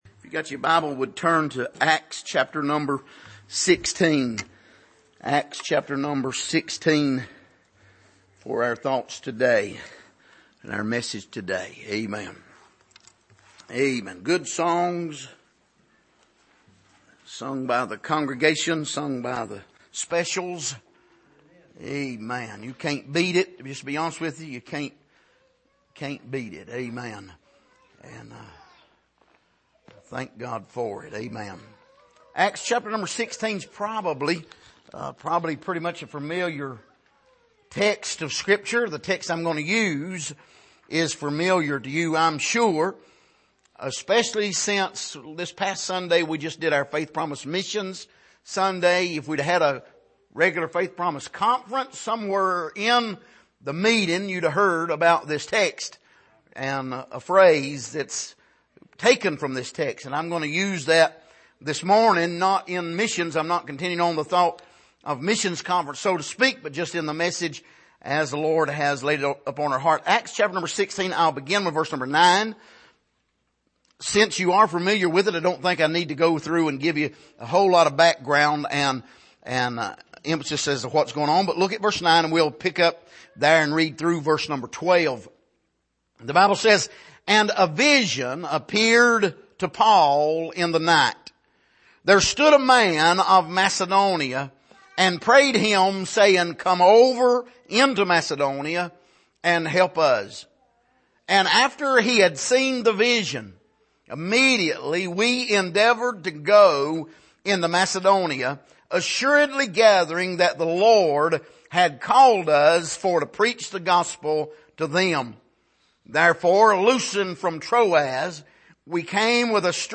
Passage: Acts 16:9-12 Service: Sunday Morning The Macedonian Call « What is Biblical Faith?